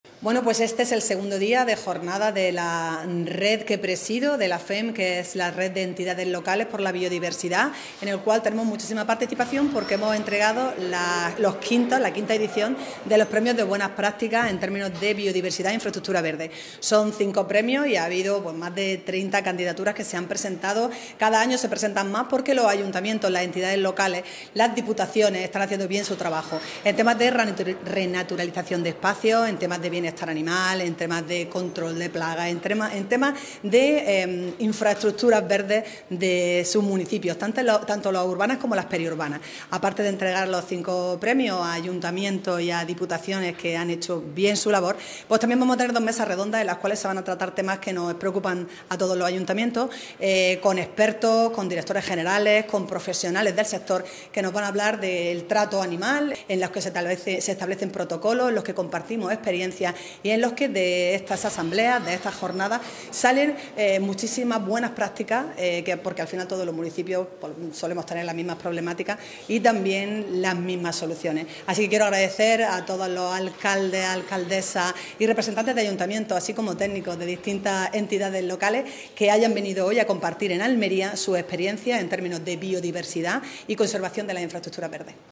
CORTE-ALCALDESA-JORNADAS-FEMP-BIODIVERSIDAD.mp3